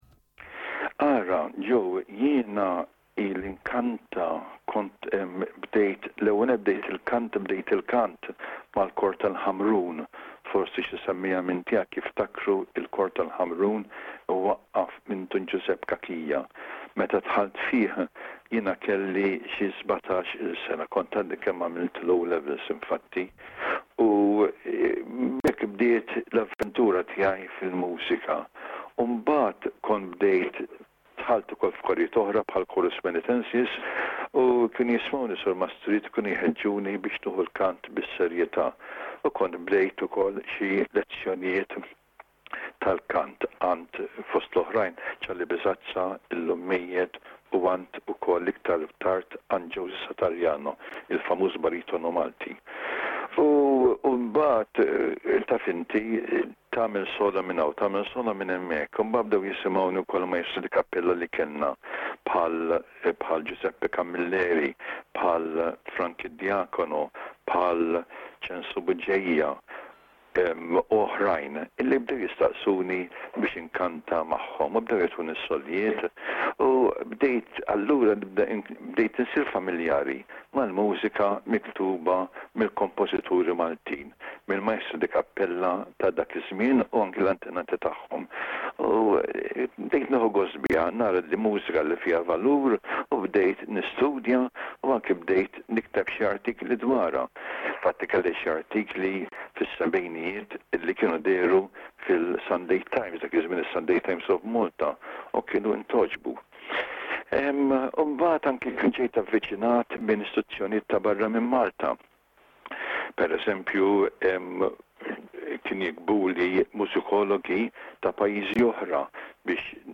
parti minn intervista mal-SBS fl-2001